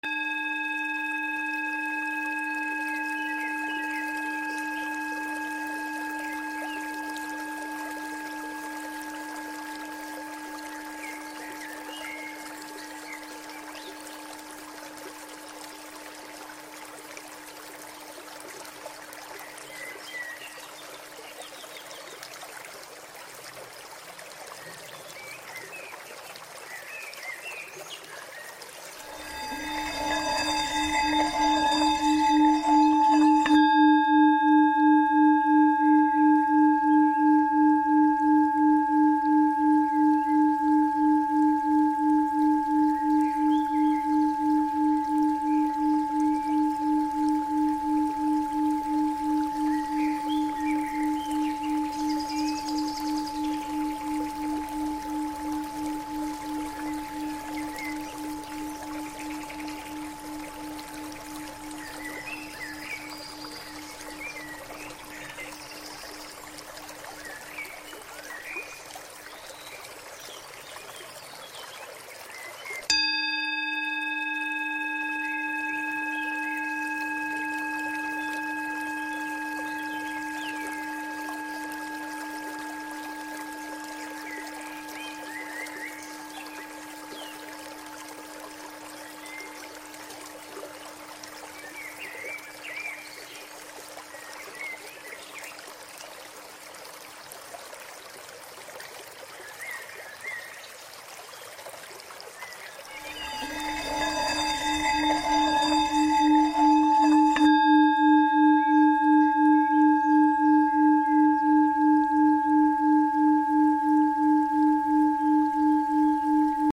Ferme les yeux, respire doucement et laisse les vibrations des bols tibétains purifier ton énergie. Ce son te ramène à toi.